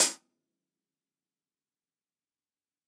TUNA_HH_2.wav